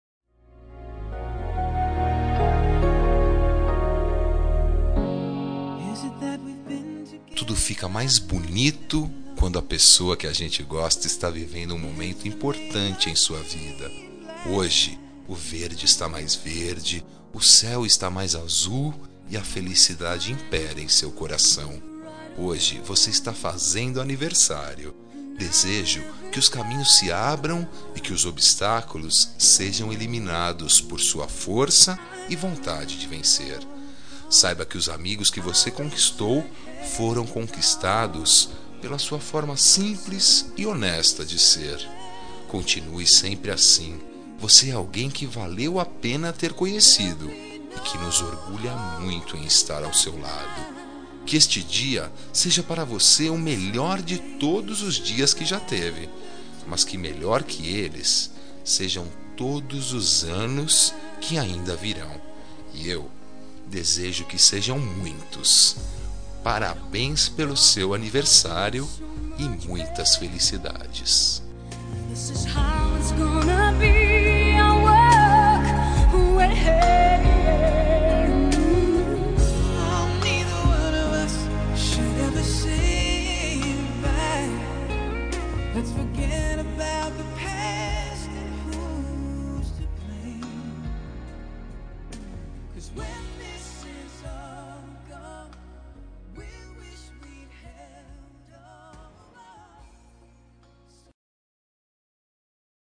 Telemensagem Aniversário de Paquera -Voz Masculina – Cód: 1269 Linda